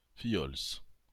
Fillols (French pronunciation: [fijɔls]